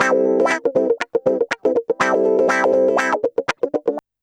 Index of /90_sSampleCDs/USB Soundscan vol.04 - Electric & Acoustic Guitar Loops [AKAI] 1CD/Partition C/04-120GROWAH